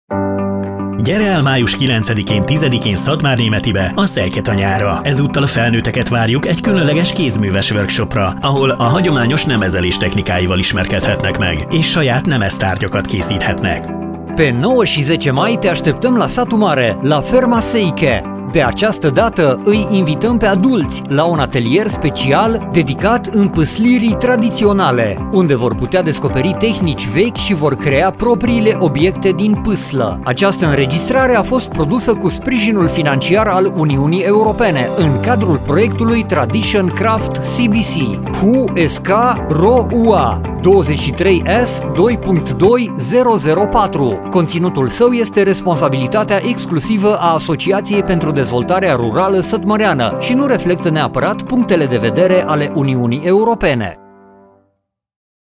Radio spot - Atelier pentru adulti - Kézműves műhely felnőtteknek